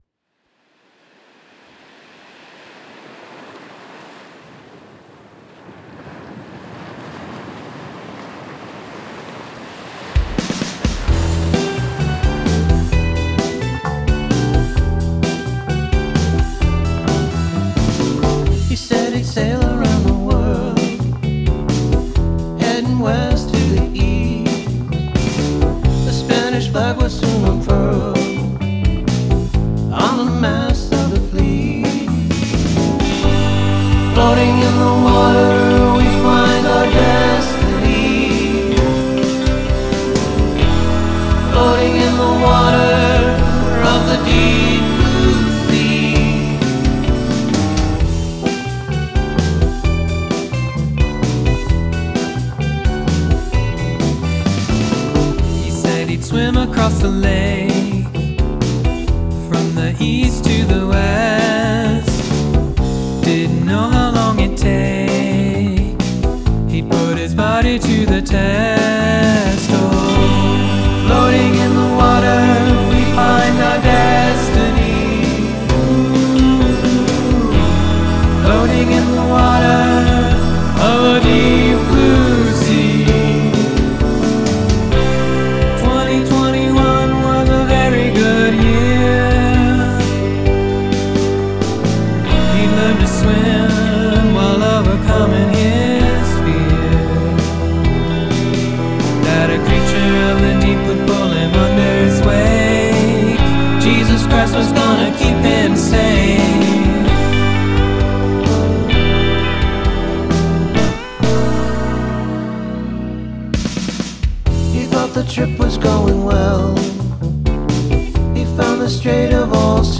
Sweet jazz chords!
That sweet guitar tone was such a tease.